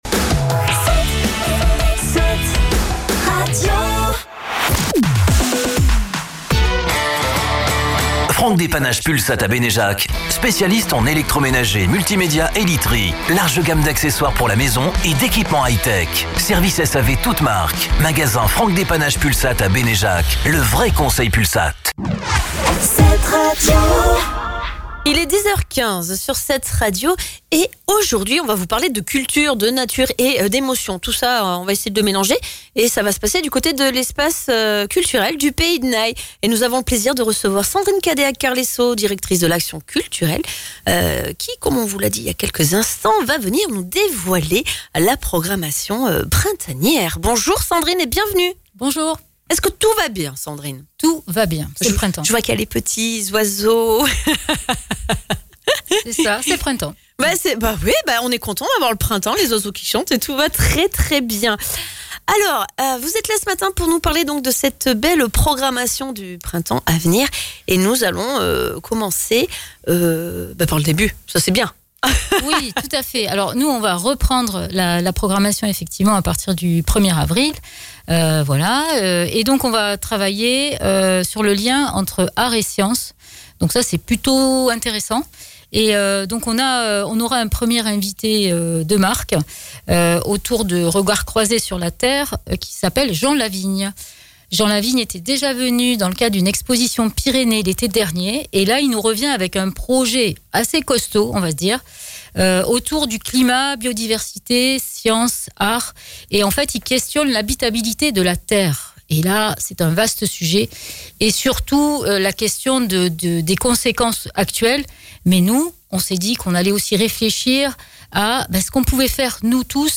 Hier matin dans La GRANDE Récré, la matinale de 7RADIO,  on a parlé culture, nature… et émotions.